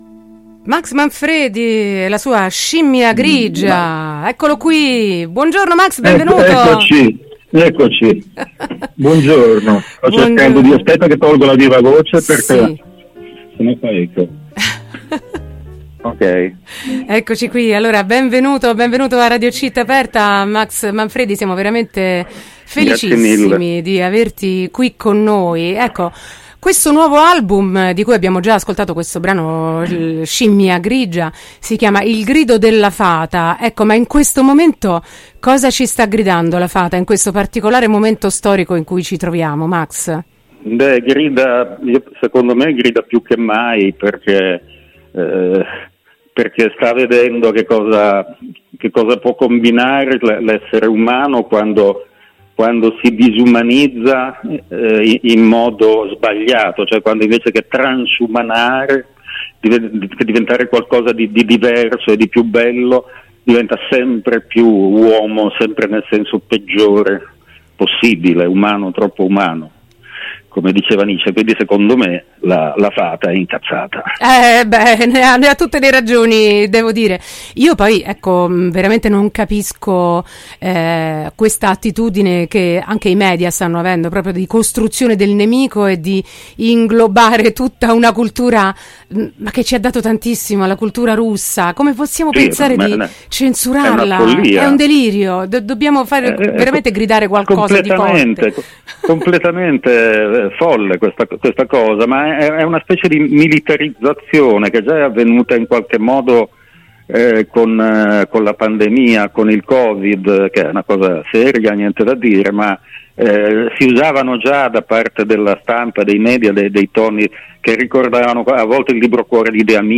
“Il grido della fata”: intervista a Max Manfredi | Radio Città Aperta